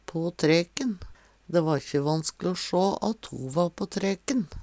See also træk (Veggli) Hør på dette ordet Ordklasse: Uttrykk Kategori: Uttrykk Attende til søk